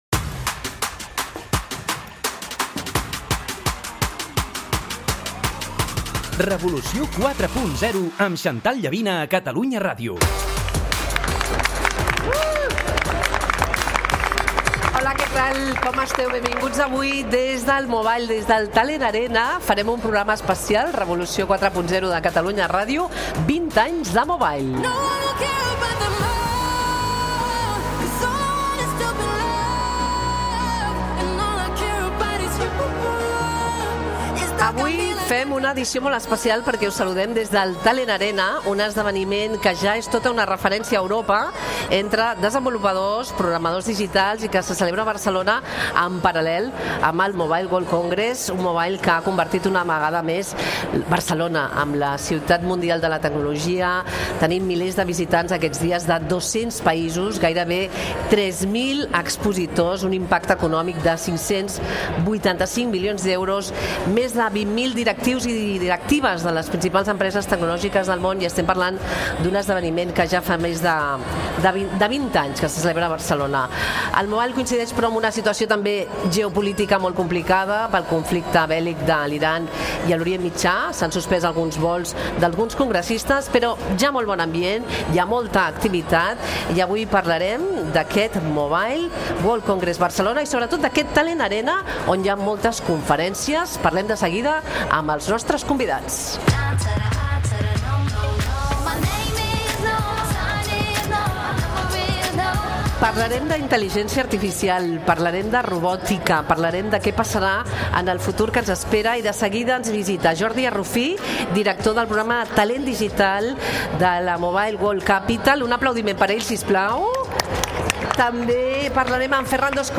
fa un programa especial des del Talent Arena del Mobile World Congress de Barcelona.